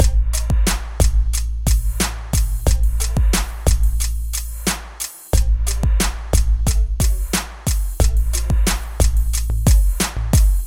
旧学校 鼓循环12
Tag: 90 bpm Hip Hop Loops Drum Loops 1.79 MB wav Key : Unknown